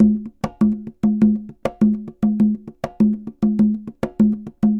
Congas_Samba 100_3.wav